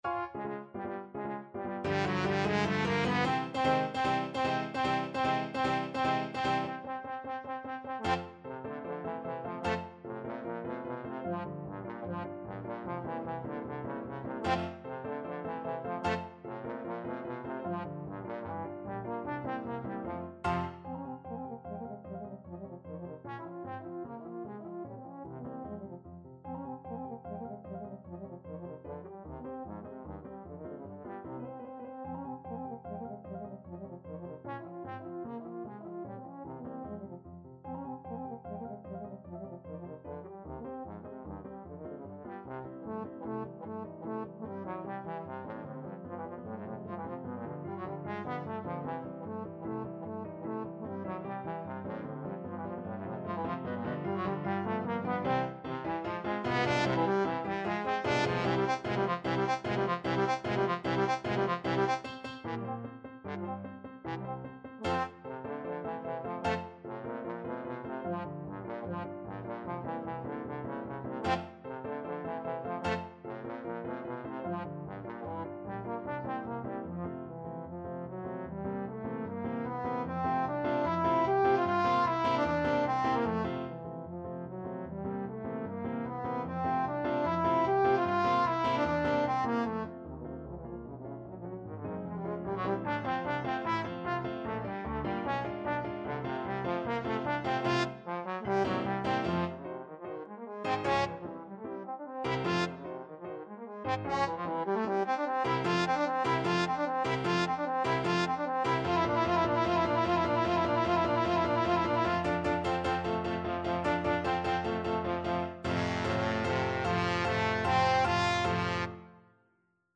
F3-G5
2/4 (View more 2/4 Music)
Allegro vivacissimo ~ = 150 (View more music marked Allegro)
Classical (View more Classical Trombone Music)